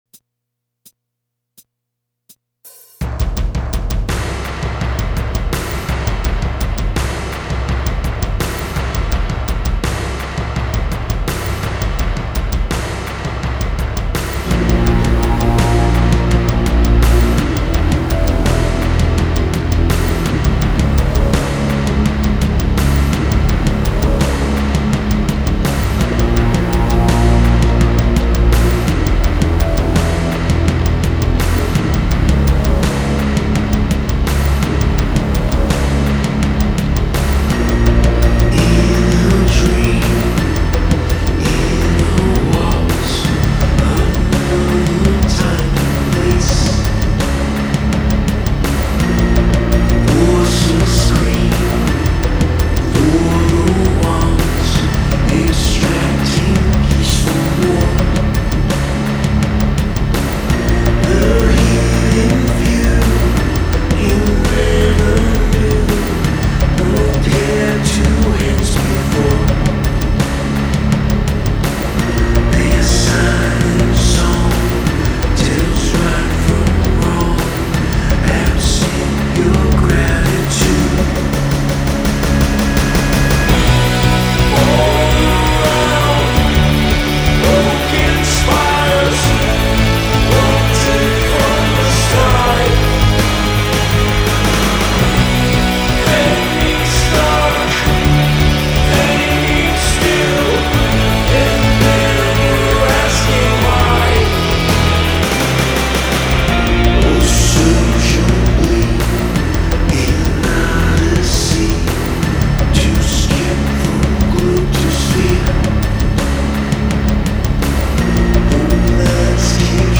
New York post-Punk/Darkwave duo via their new album
guitar and vocals
bass guitar